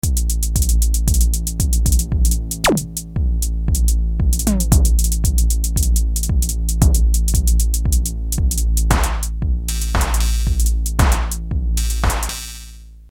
Sampling with DSI TEMPEST